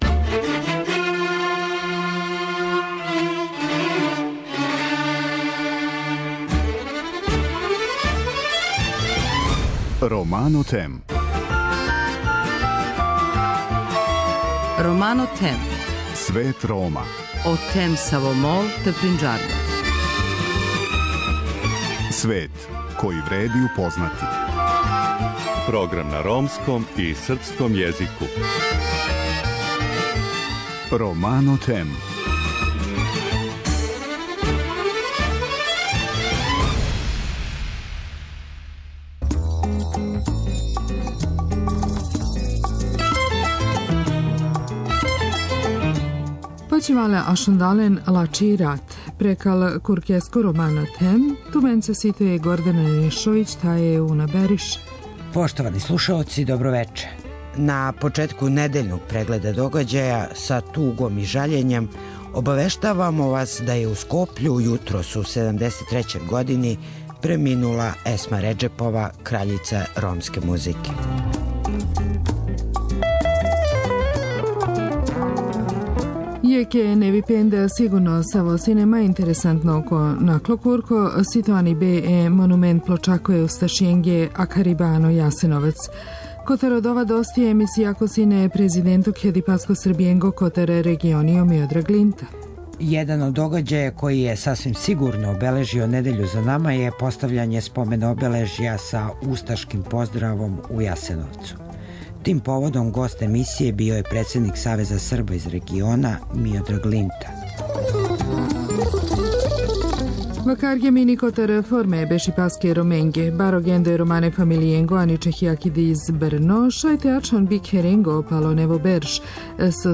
Тим поводом гост емисије био је председник Савеза Срба из региона Миодраг Линта.